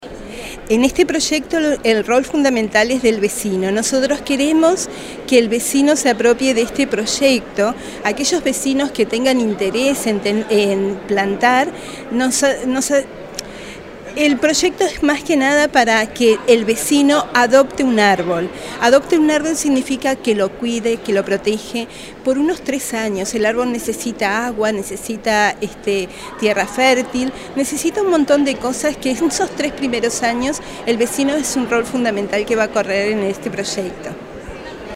Este sábado 25 de junio se presentó en el Hall del Centro Cívico Costa Urbana el proyecto Canelón, una iniciativa de la Comisión Pro Fomento de Shangrilá que cuenta con el apoyo de la Intendencia de Canelones y del Municipio de Ciudad de la Costa.